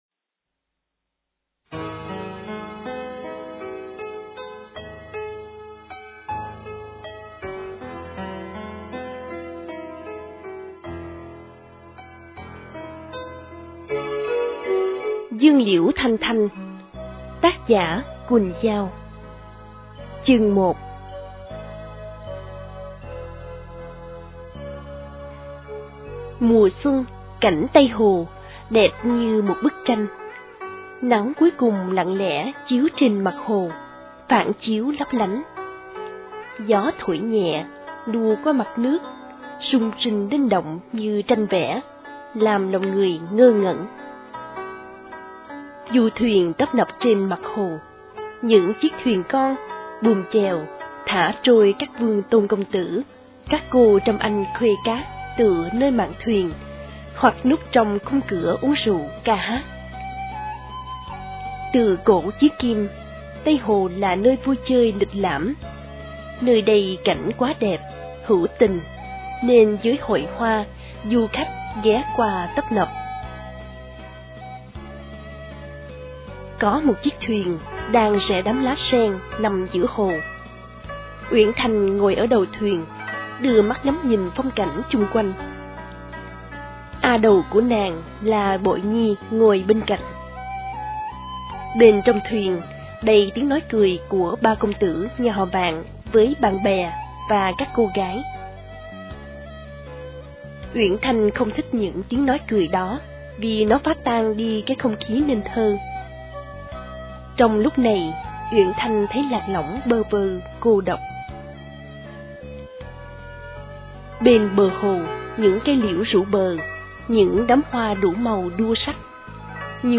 Sách nói | Dương Liễu Thanh Thanh